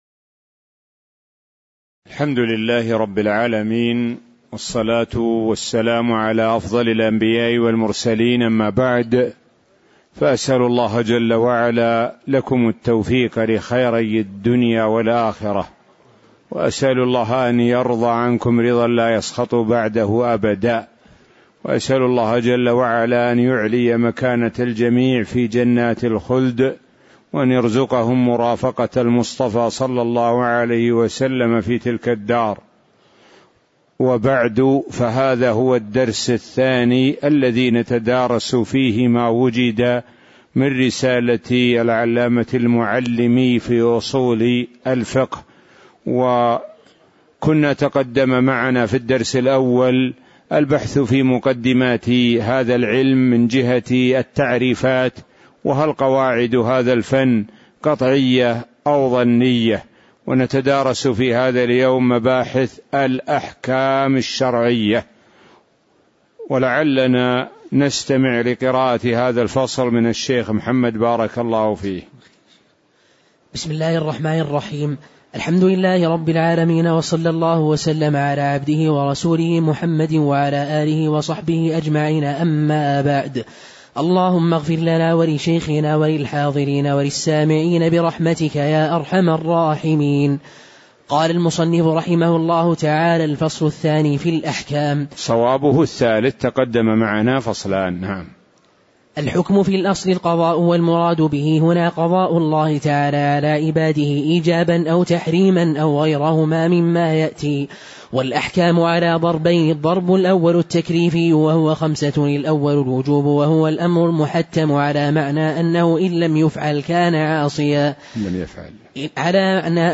تاريخ النشر ٣ جمادى الأولى ١٤٣٩ هـ المكان: المسجد النبوي الشيخ: معالي الشيخ د. سعد بن ناصر الشثري معالي الشيخ د. سعد بن ناصر الشثري الفصل الثالث في الأحكام (002)